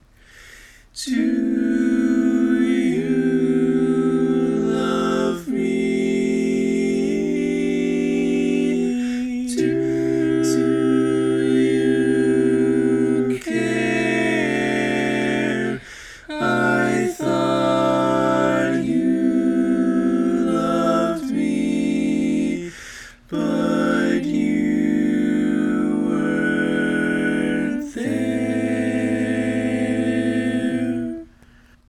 Key written in: A Minor
How many parts: 4
Type: Barbershop
All Parts mix: